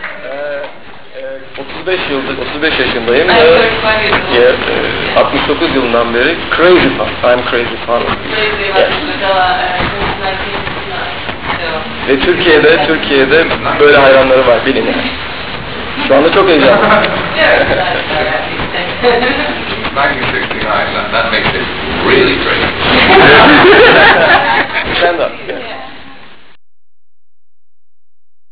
" Voices are taken from first Press Conferance in Istanbul, 11 July 1991
11 Temmuz 1991 basın toplantısından alınmıştır "